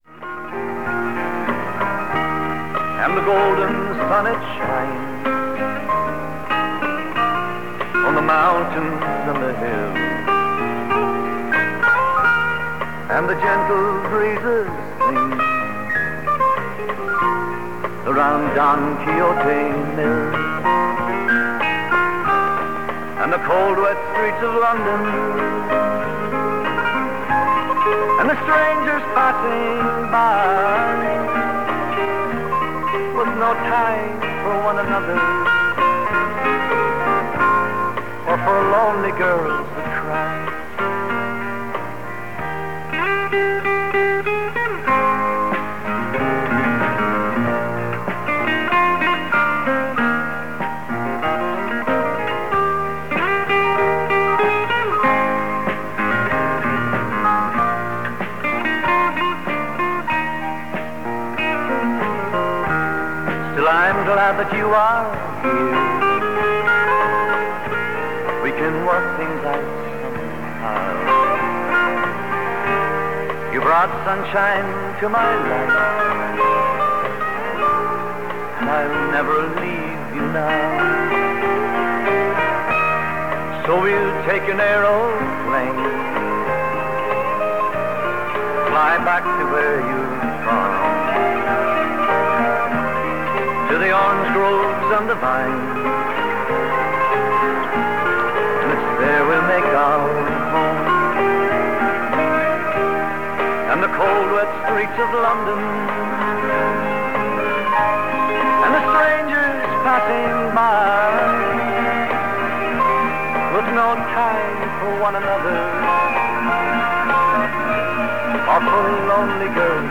afternoon show